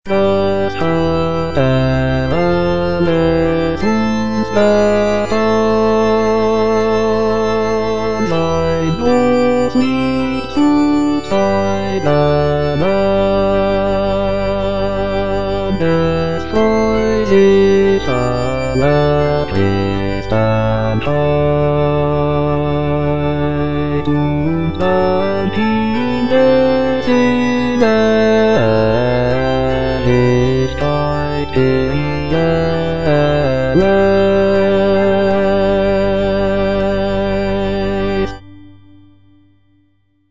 J.S. BACH - CANTATA "SEHET, WELCH EINE LIEBE" BWV64 Das hat er Alles uns getan - Bass (Voice with metronome) Ads stop: auto-stop